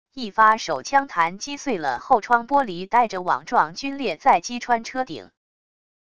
一发手枪弹击碎了后窗玻璃带着网状龟裂再击穿车顶wav音频